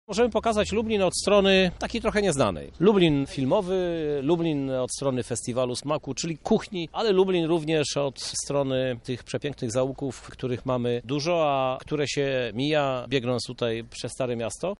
– Chcemy pokazać Lublin z różnych stron – podkreśla prezydent miasta Krzysztof Żuk: